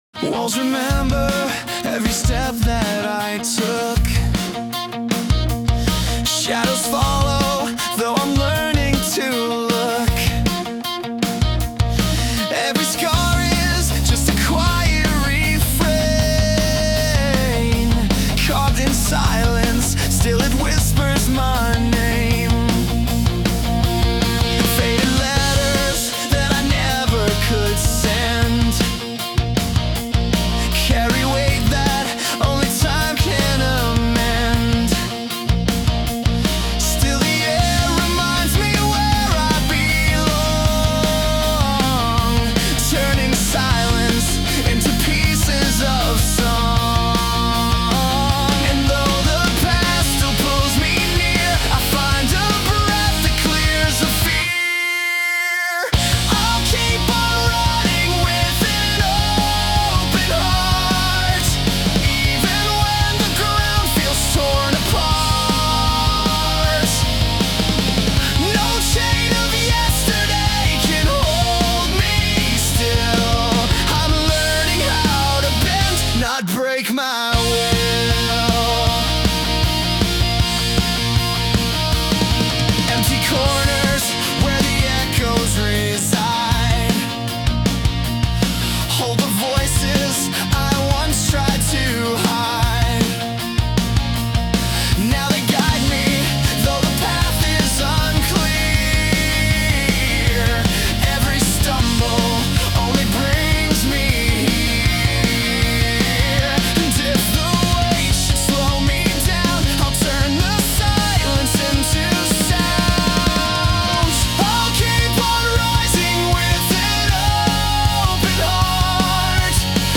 男性ボーカル洋楽洋楽 男性ボーカル作業BGMアップテンポロック切ない
男性ボーカル（洋楽・英語）曲です。
前回の洋楽男性ボーカルに続き、ロックで速めテンポですが、今回はかなりエモいサウンドにしてみました！